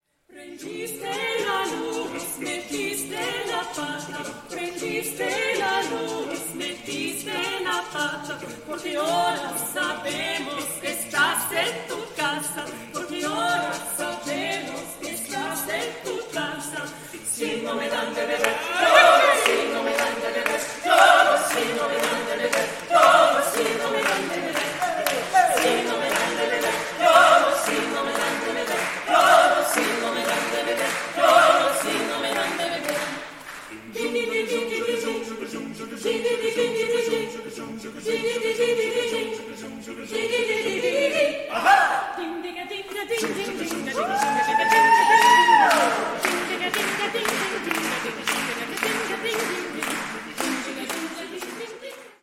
Soprano
Bass
Mezzo-soprano
Tenor